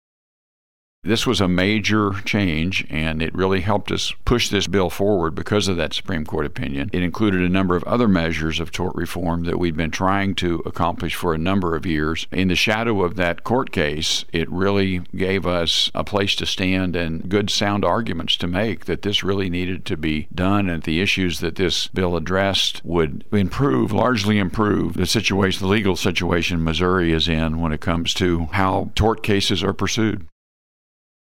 4. Senator Emery says using last month’s Missouri Supreme Court decision is helpful.